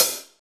paiste hi hat1 close.wav